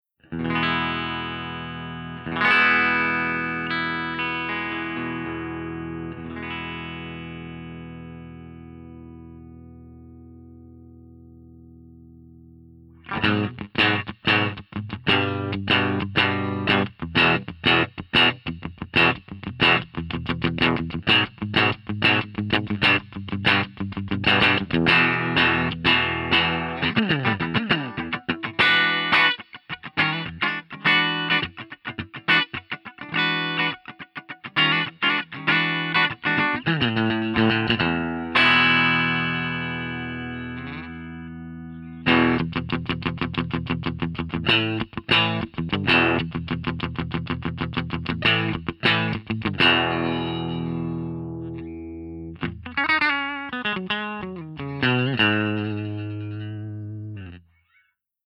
045_FENDER75_PUNCHYEQ_SC.mp3